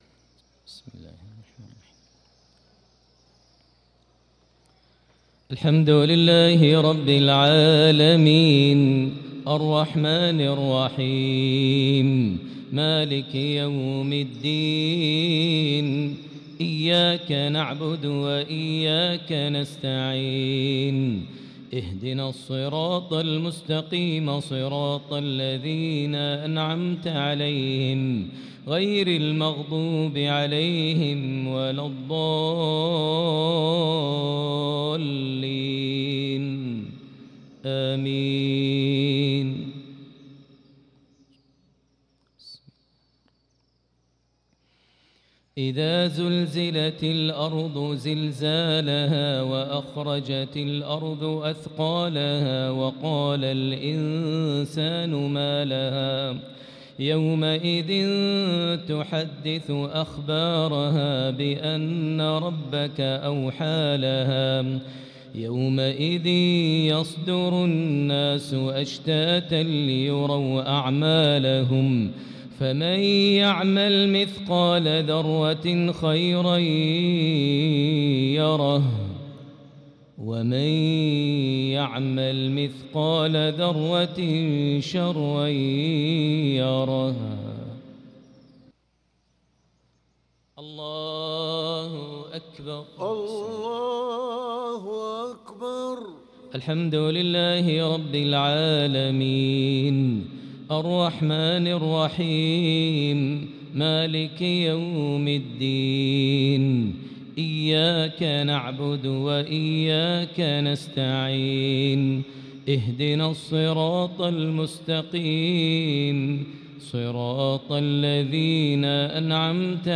صلاة المغرب للقارئ ماهر المعيقلي 3 شعبان 1445 هـ